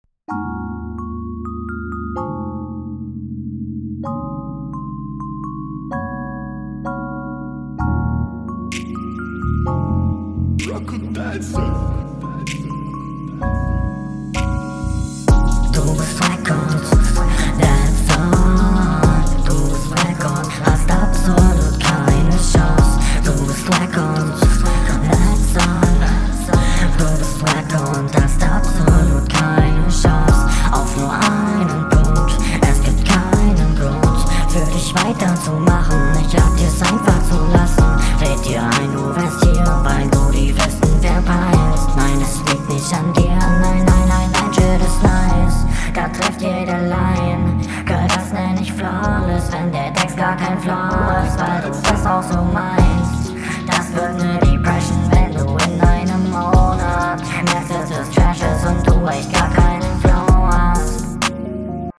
Flow: Gut, er ist Onbeat, aber leider ist da keine Spur von Verständlichkeit.